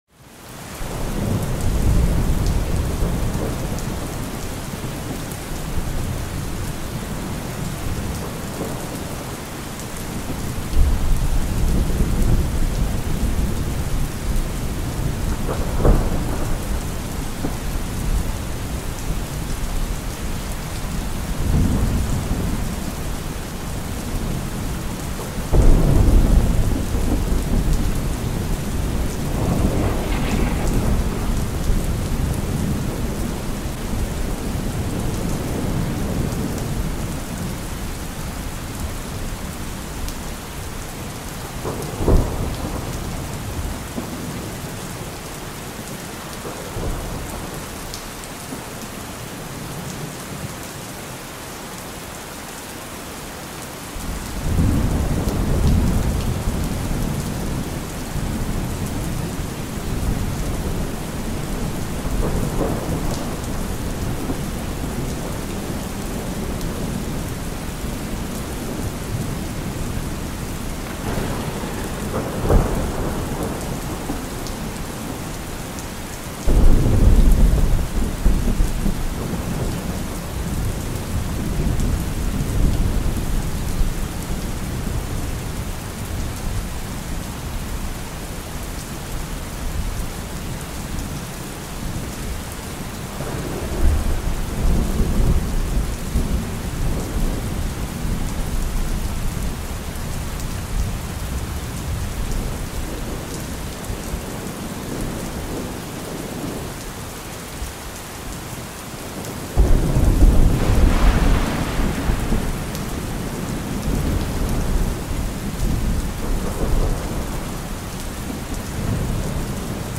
Click play below to hear something calming…
rain.mp3